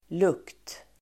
Uttal: [luk:t]